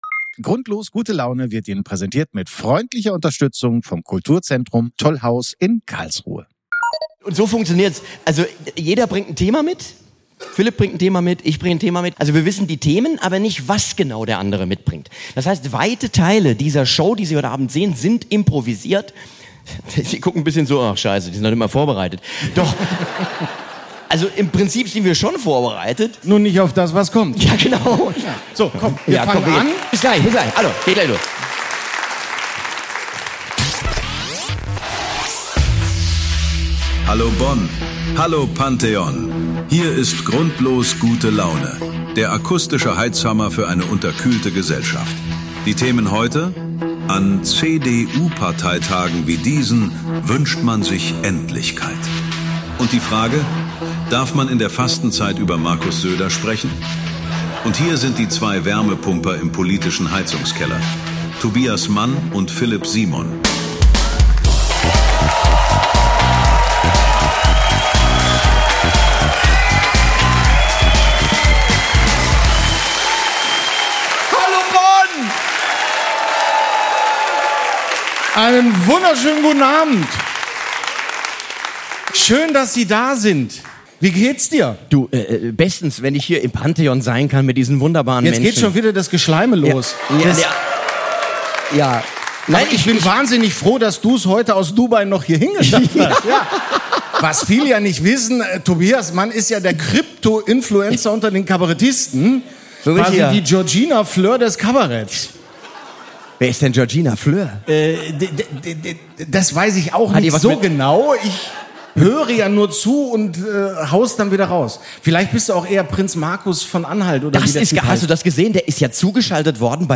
Tobias Mann und Philip Simon live aus dem Pantheon in Bonn! Ein enthusiastisches Publikum, zwei hochmotivierte Satiriker, eine ermüdende Rede von Friedrich Merz und ein verstörender Auftritt von Markus Söder.